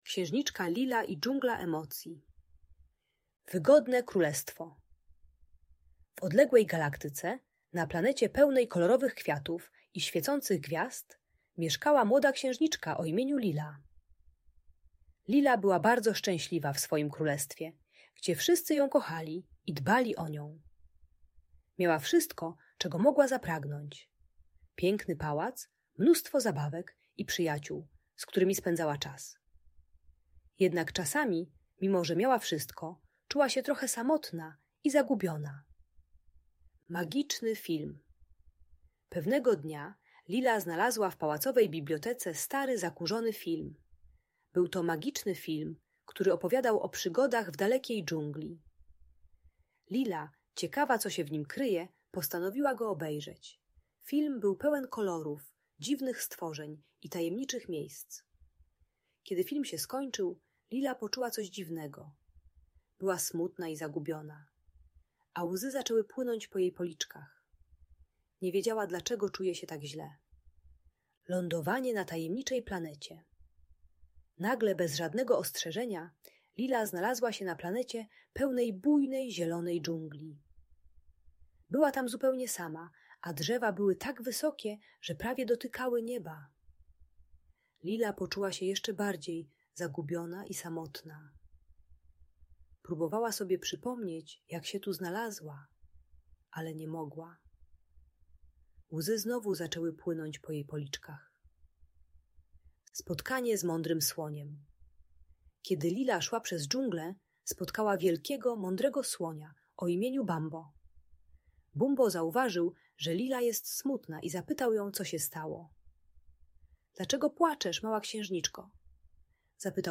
Księżniczka Lila i Dżungla Emocji - Story - Audiobajka dla dzieci